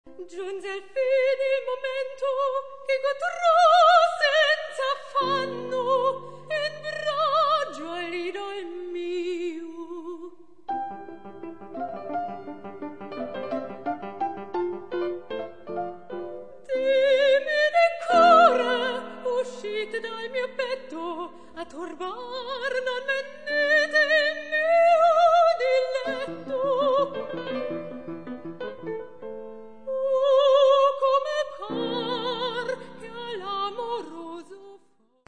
Sopran
Flügel